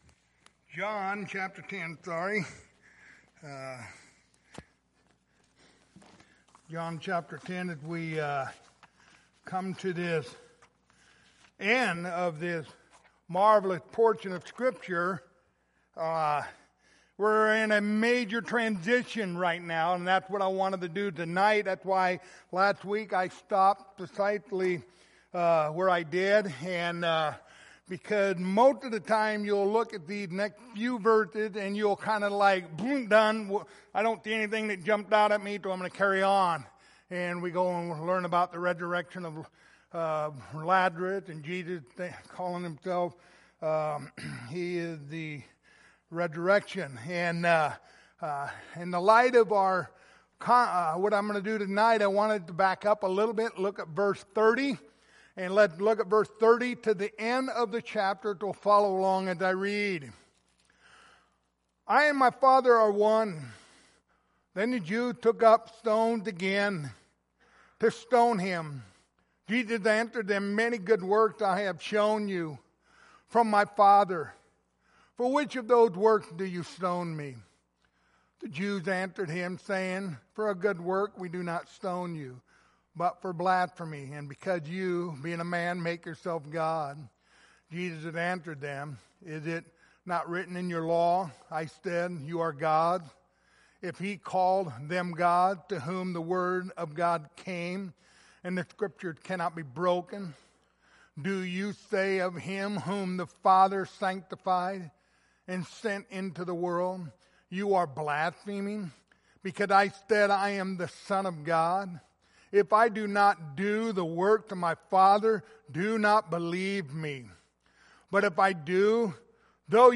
The Gospel of John Passage: John 10:30-42 Service Type: Wednesday Evening Topics